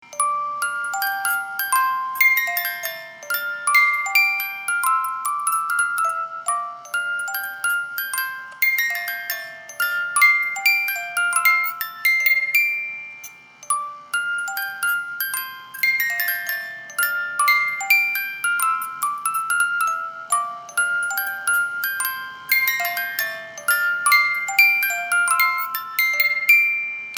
因為機械設計的限制，十八音梳機芯，只有十五秒左右的旋律，上發條後，這十五秒左右的旋律會重覆的撥放，直到發條鬆了為止！
機芯轉動時皆會有運轉聲、金屬磨擦聲(電動機芯也會有馬達運轉聲)，請知悉。